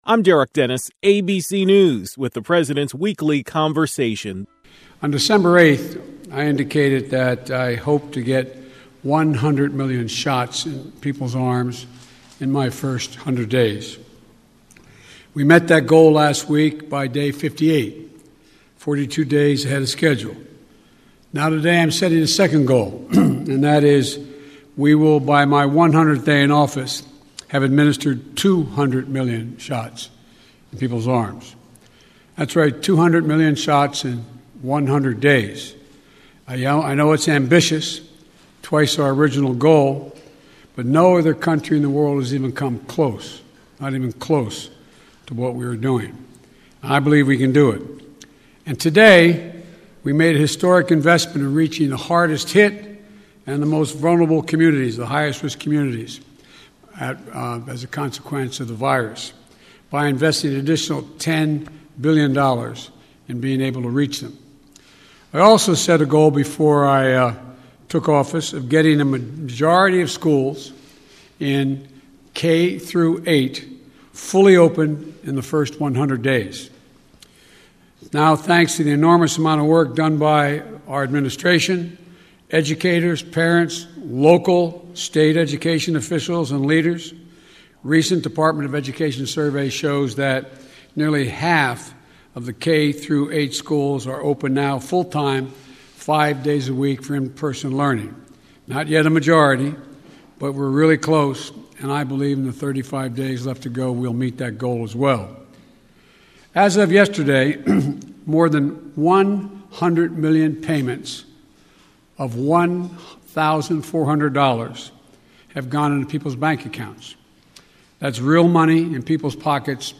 President Joe Biden spoke about the Jewish celebration of Passover.